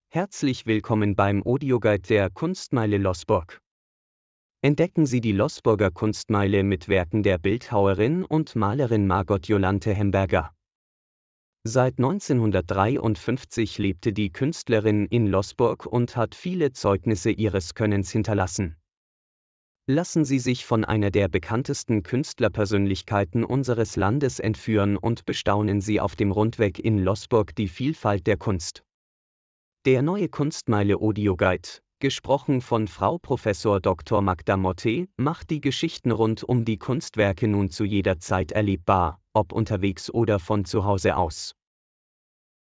Audioguide der "Kunstmeile Loßburg"